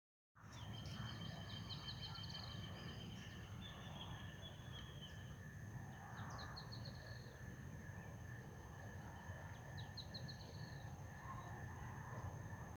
Cochicho (Anumbius annumbi)
Nome em Inglês: Firewood-gatherer
Localidade ou área protegida: Concordia
Condição: Selvagem
Certeza: Observado, Gravado Vocal